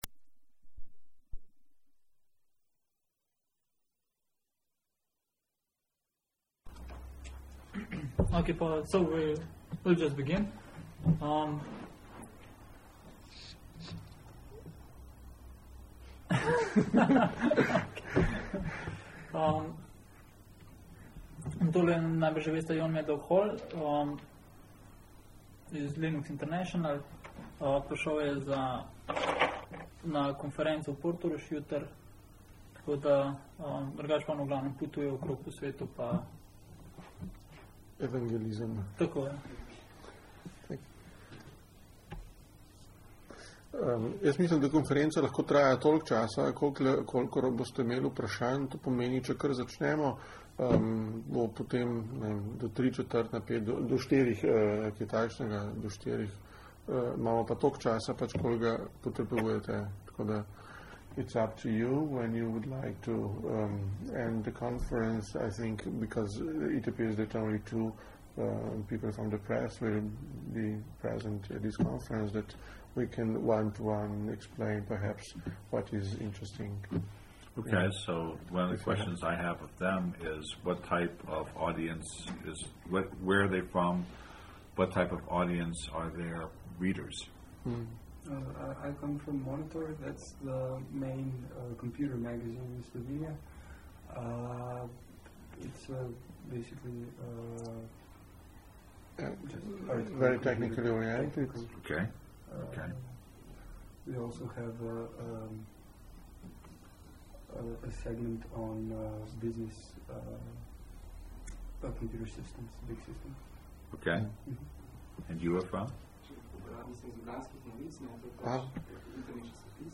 Sound recording of the press conference held at the occasion of Jon maddog Hall's visit to Slovenia. 2002-09-29 Hermes SoftLab, Ljubljana